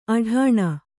♪ aḍhāṇa